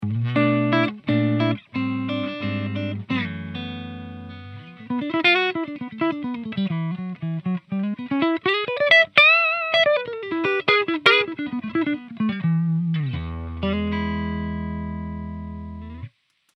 Country riff 3